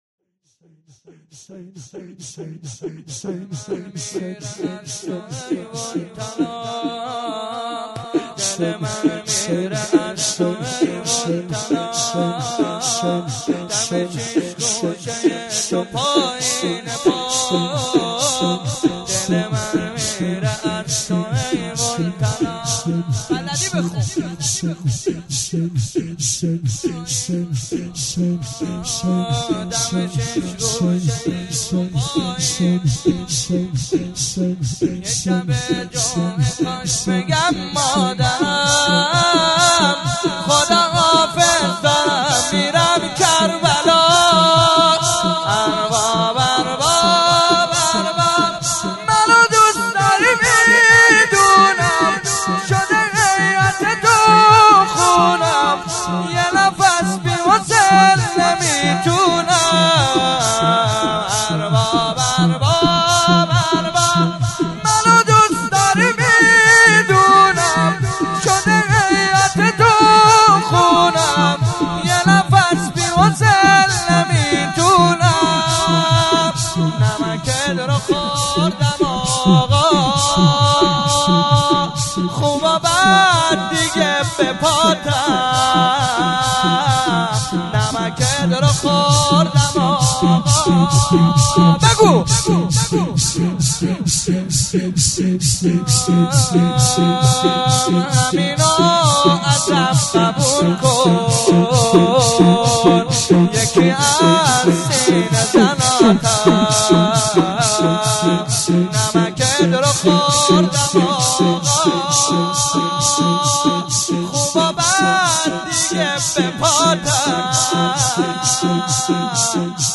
06.sineh zani2.mp3